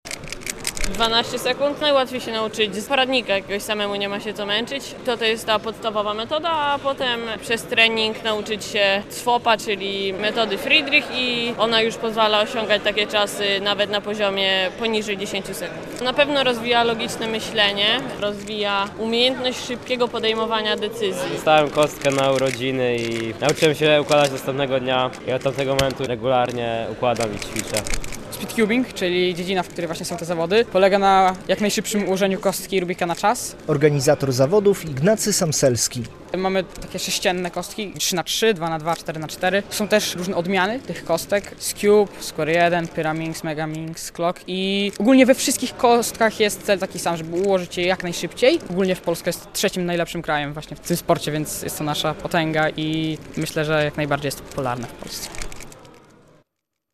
W Białymstoku zorganizowano zawody w tak zwanym speedcubingu - relacja